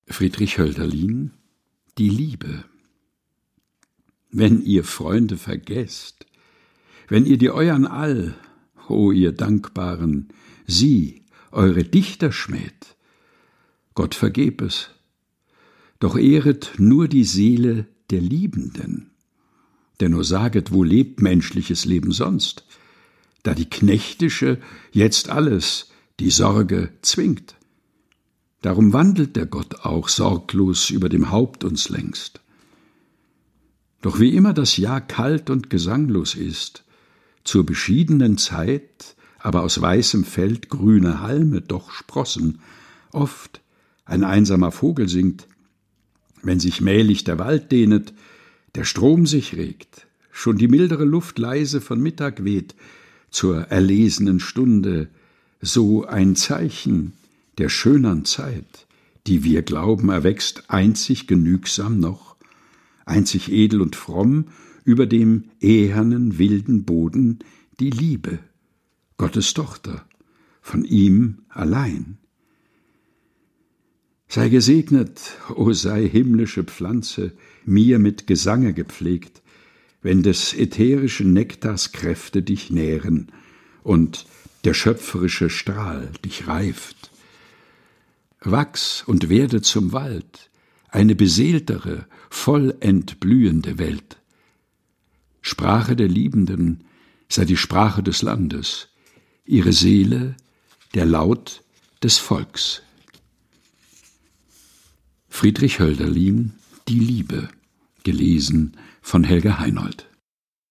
Texte zum Mutmachen und Nachdenken - vorgelesen
im heimischen Studio vorgelesen